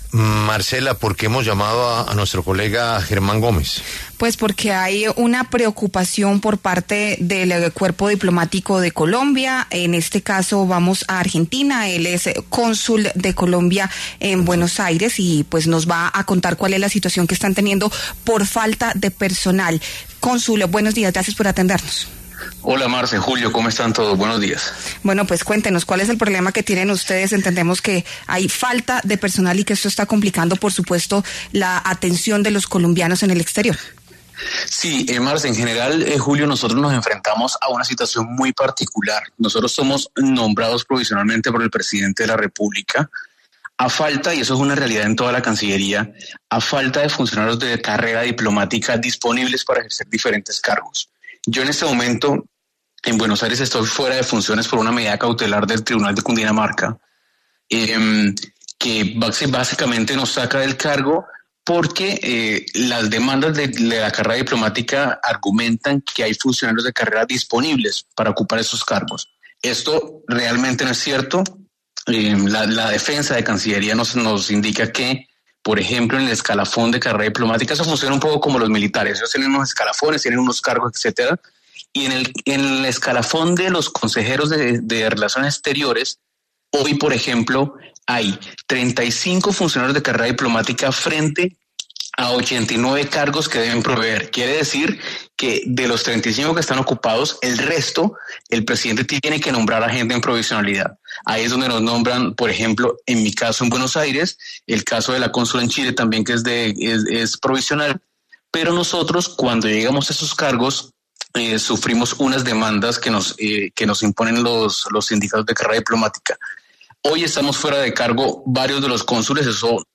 La W conversó con el cónsul en Buenos Aires. Germán Gómez, quien está apartado de su cargo por una demanda.
Germán Gómez, cónsul de Colombia en Buenos Aires, Argentina, pasó por los micrófonos de La W, con Julio Sánchez Cristo, para hablar sobre la falta de personal en la entidad.